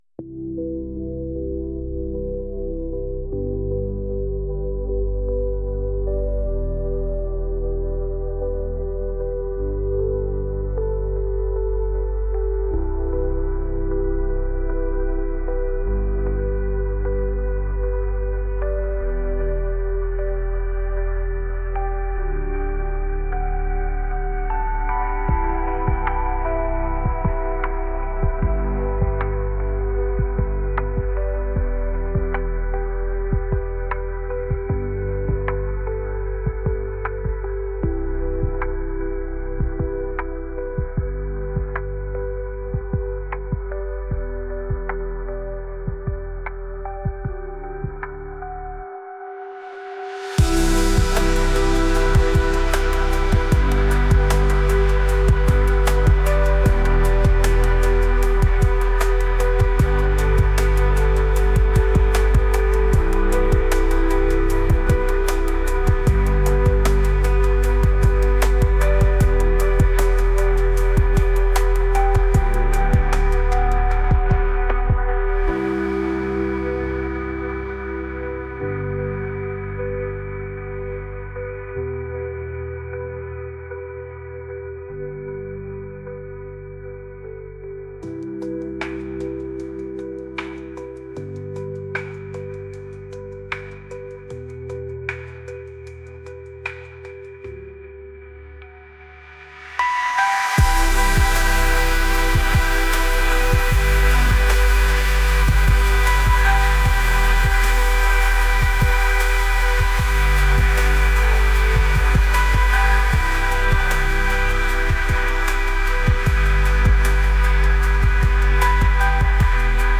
dreamy | atmospheric